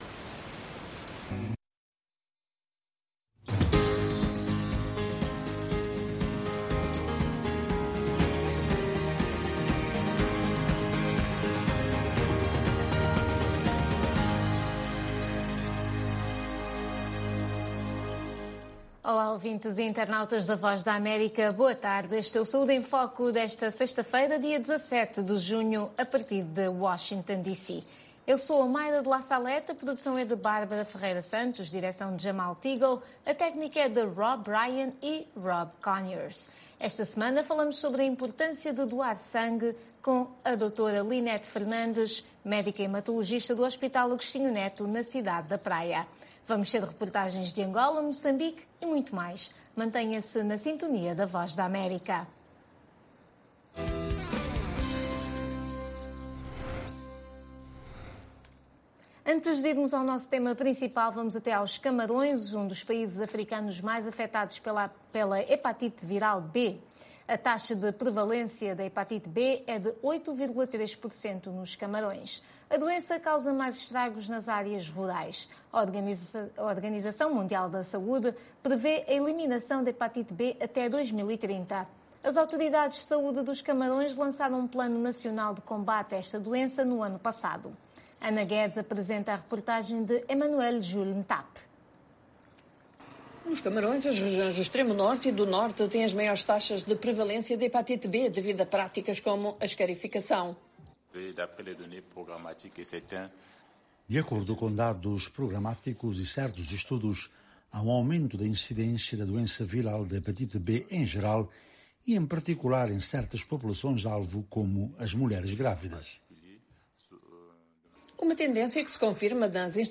O programa é transmitido às sextas-feiras às 16h30 UTC. Todas as semanas falamos sobre saúde, dos Estados Unidos da América para o mundo inteiro, com convidados especiais no campo social e da saúde.